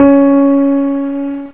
pianos
g-piano5.mp3